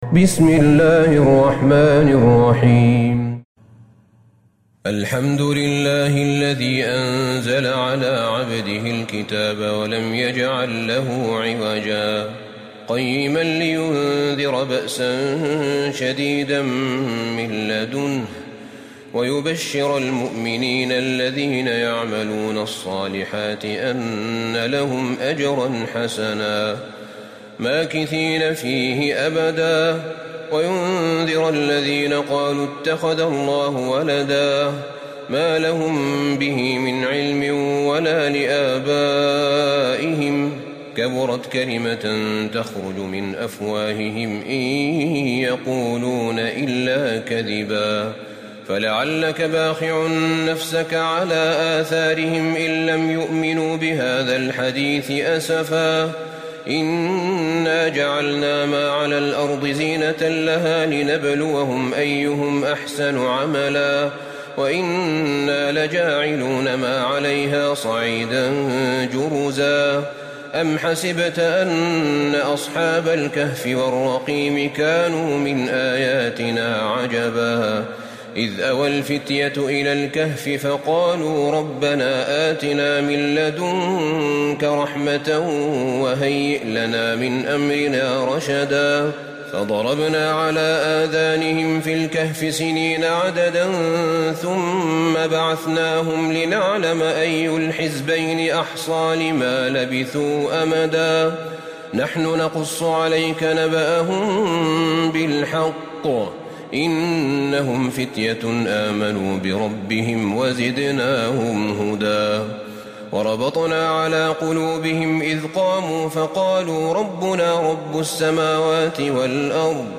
سورة الكهف Surat Al-Kahf > مصحف الشيخ أحمد بن طالب بن حميد من الحرم النبوي > المصحف - تلاوات الحرمين